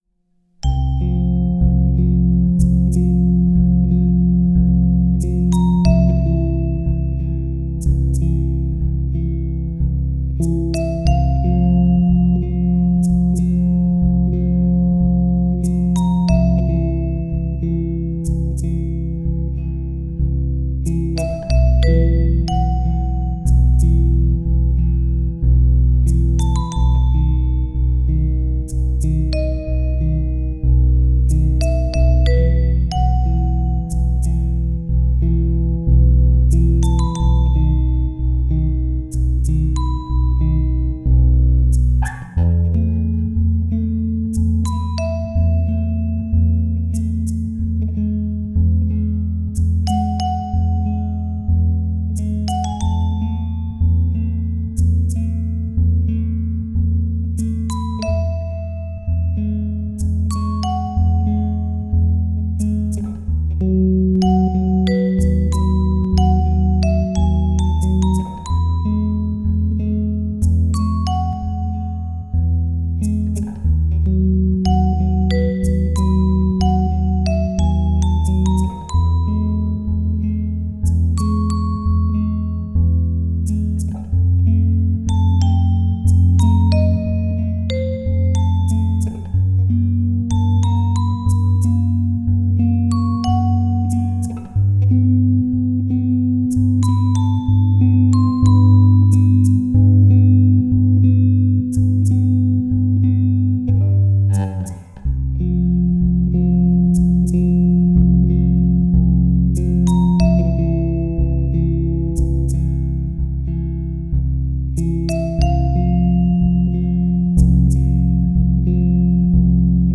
[♪] Ring Bass & MIDI Vibraphone, Shaker '20.10.08 宅録
メロディーは打ち込みで「それだけではちょっと寂しいな」と思い、シェイカーも追加してみました。 曲名は、終わりがハッキリせず、ずっと繰り返すような感じからつけてみました。